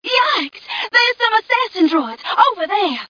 1 channel
mission_voice_fmca012.mp3